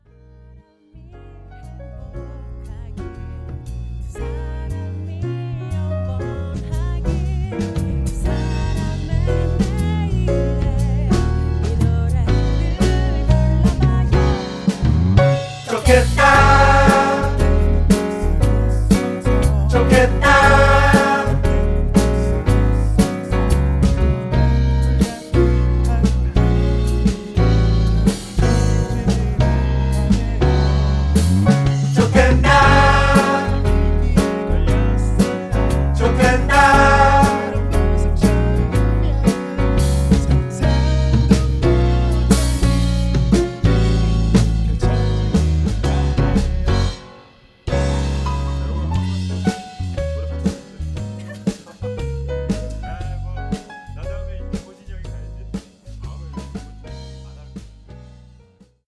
음정 -1키 4:16
장르 가요 구분 Voice MR